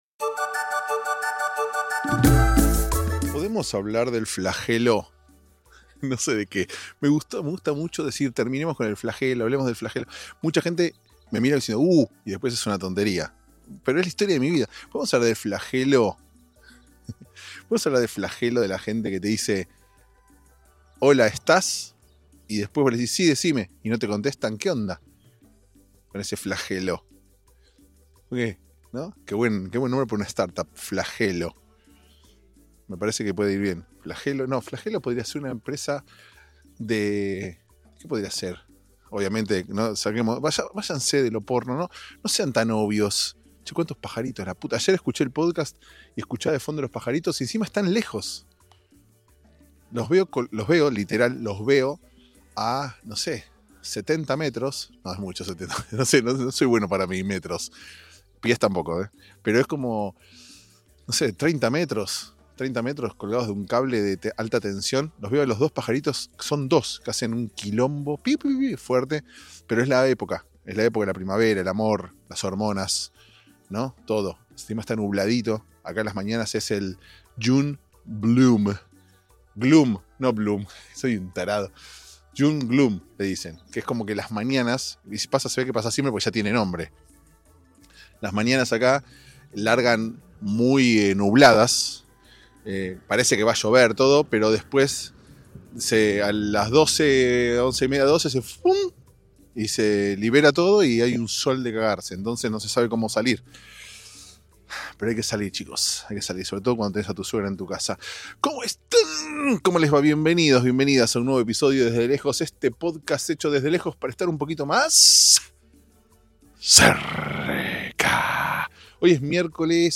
Un flagelo grabar un episodio como este en medio del flagelo de los pájaros cantando fuerte y la suegra opinando sobre temas de los que, obvio, nadie le pidió opinión.